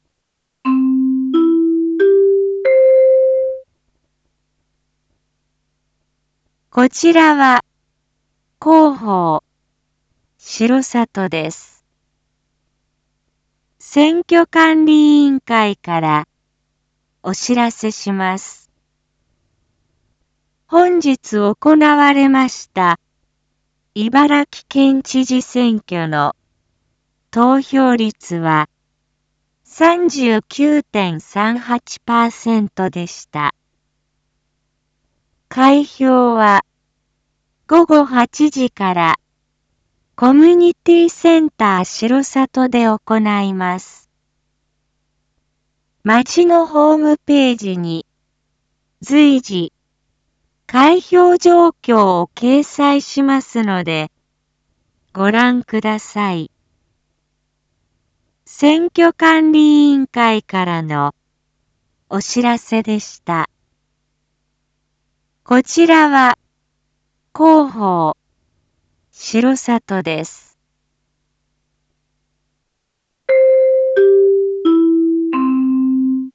Back Home 一般放送情報 音声放送 再生 一般放送情報 登録日時：2021-09-05 19:41:17 タイトル：R3.9.5 19時40分放送 県知事選挙投票率 インフォメーション：こちらは広報しろさとです。